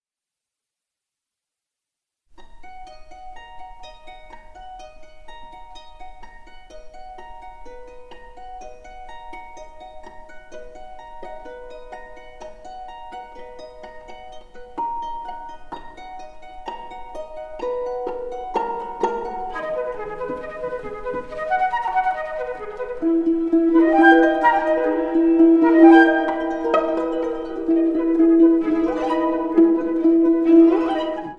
Harp
Flute
Viola Released